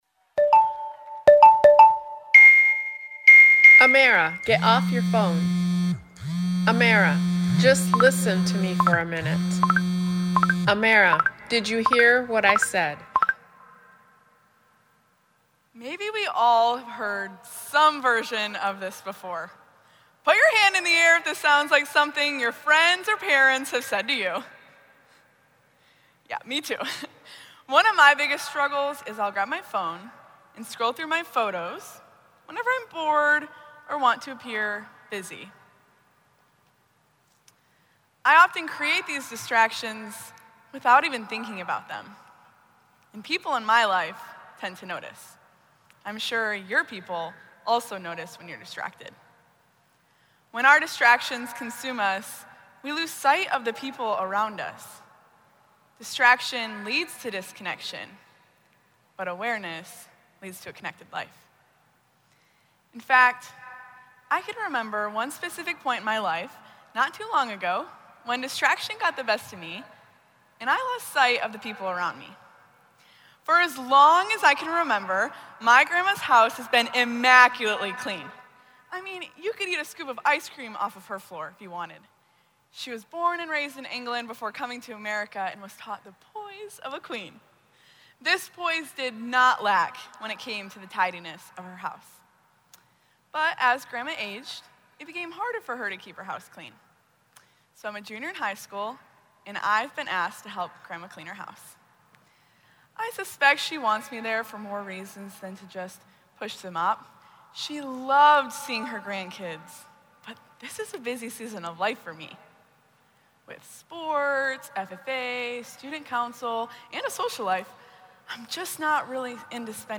Retiring Address